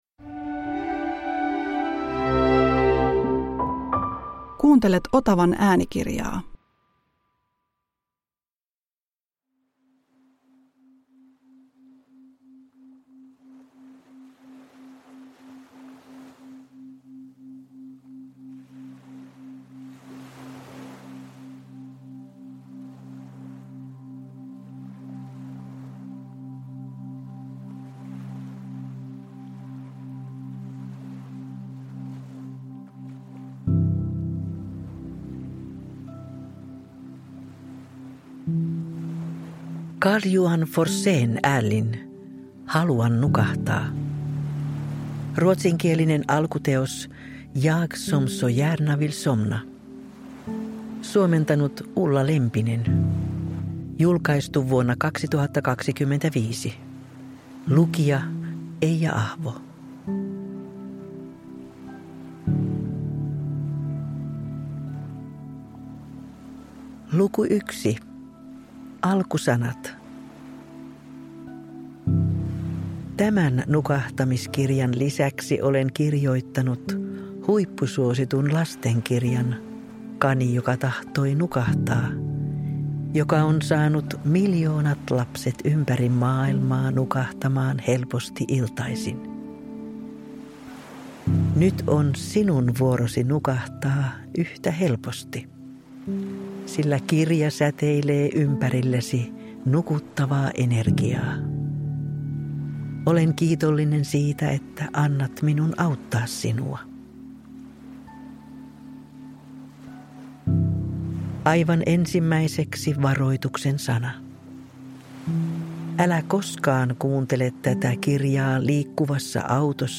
Haluan nukahtaa – Ljudbok
Äänikirjan musiikki ja äänimaailma on yhdistetty binauraalisiin lyönteihin syvän rentoutumisen aikaansaamiseksi ja unen laadun parantamiseksi.